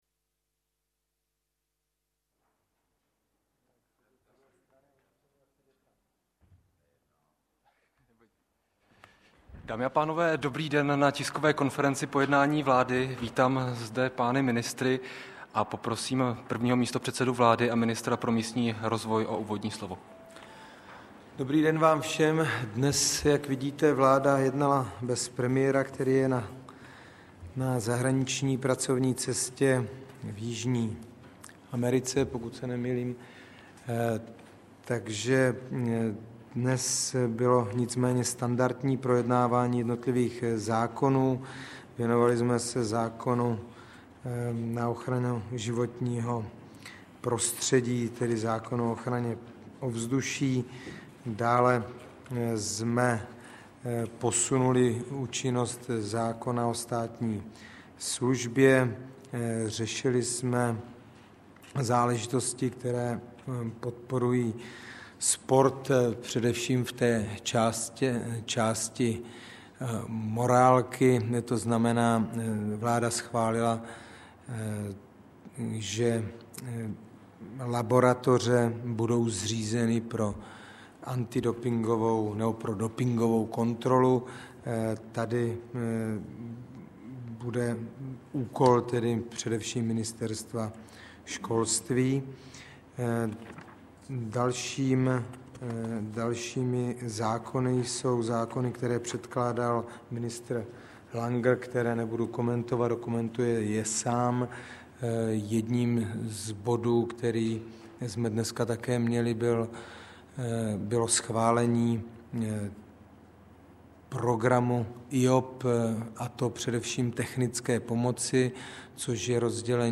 Tisková konference po jednání vlády ČR 14. května 2008